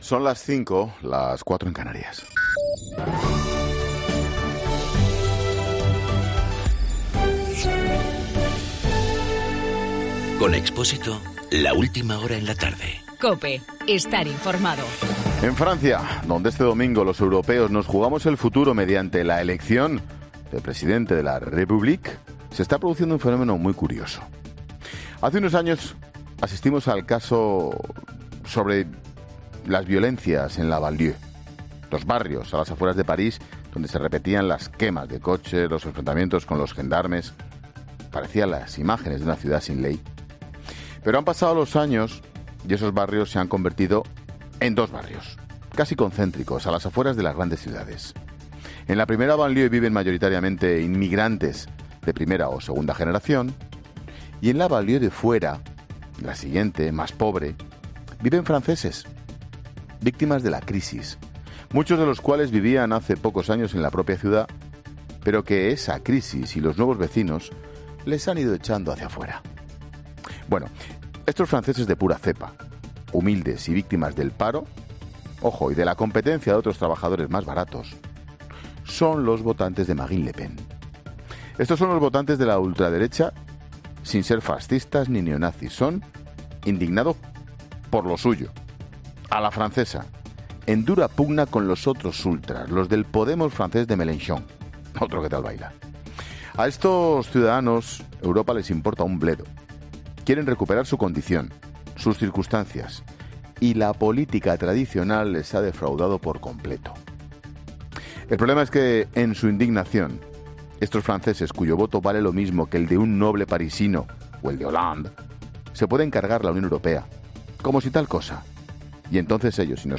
AUDIO: Monólogo 17h.